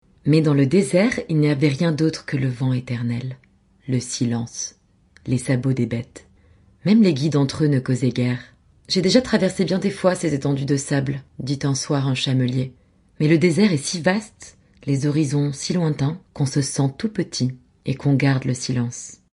Voix-off- Maquette Livre audio
- Basse